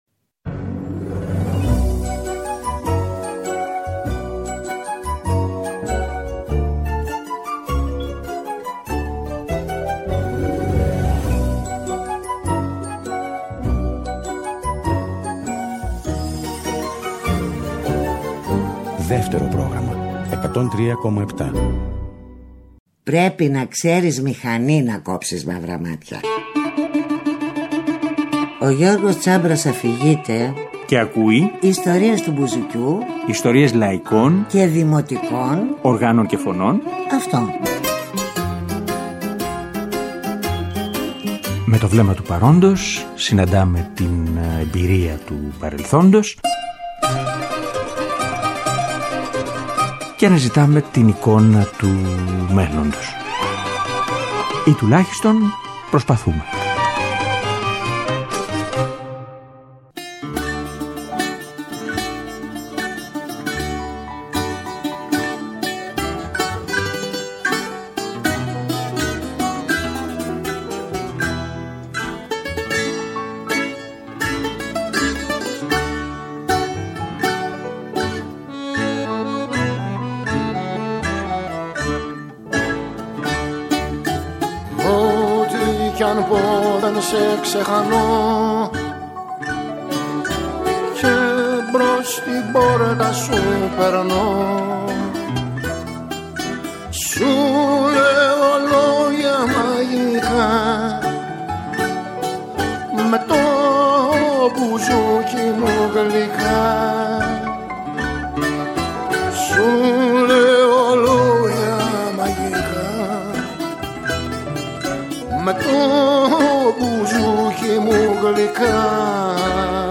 Επιλογές ποικίλης λαϊκής ύλης, αναμενόμενες και μη.